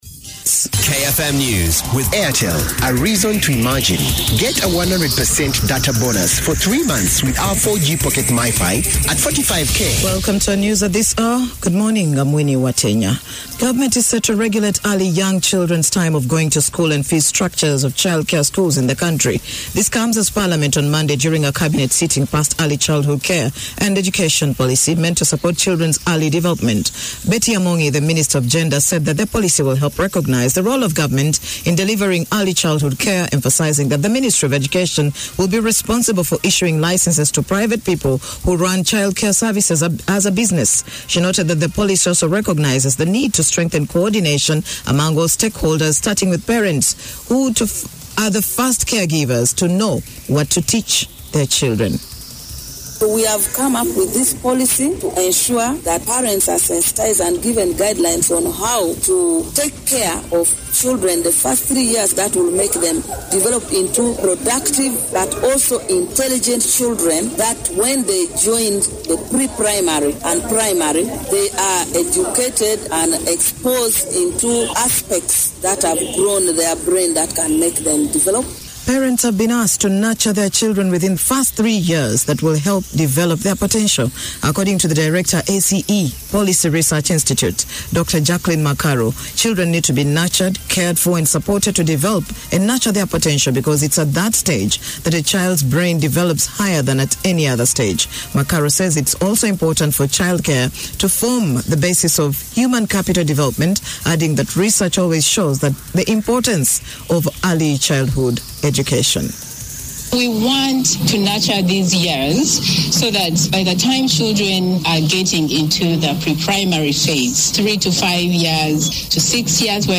Audio News
ACE-KFM-News-.mp3